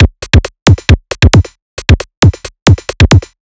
Lazer Break 135.wav